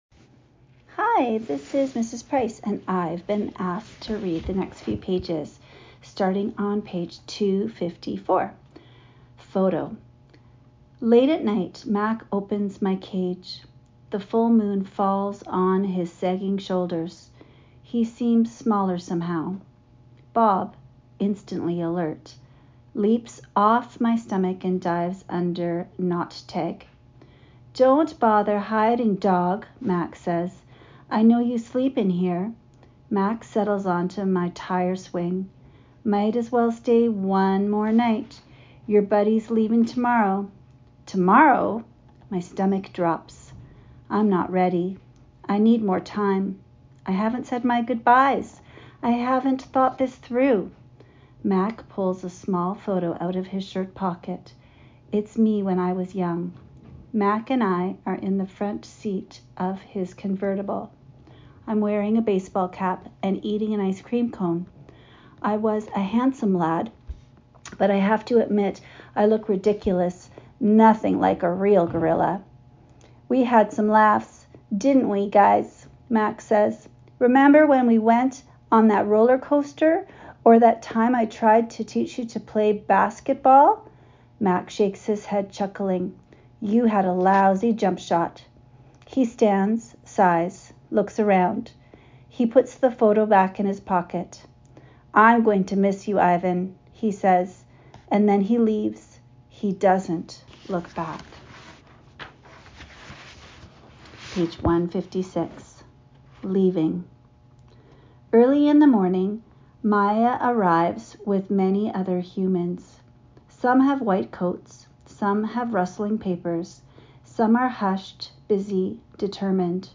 The One and Only Ivan Reading for May 7